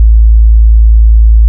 **🔊 SFX PLACEHOLDERS (23 WAV - 1.5MB):**
**⚠  NOTE:** Music/SFX are PLACEHOLDERS (simple tones)
explosion.wav